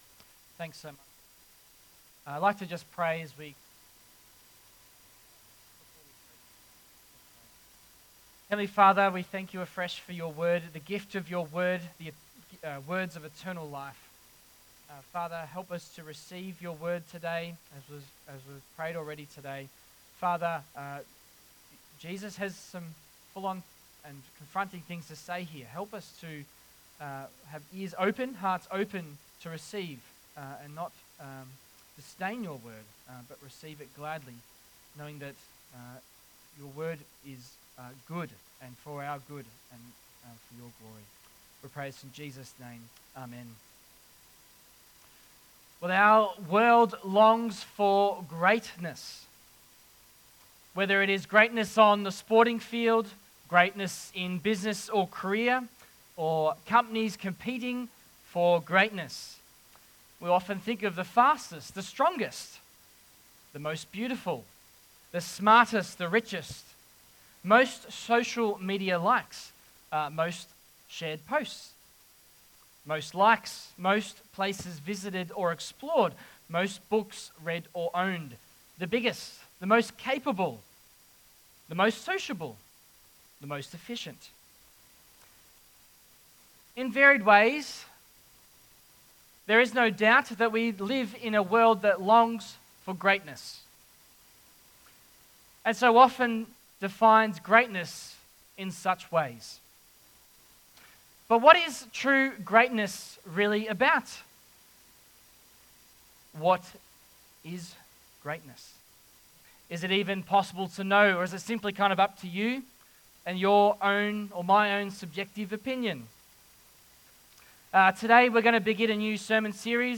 Passage: Matthew 18:1-14 Service Type: AM Geelong Christian Reformed Church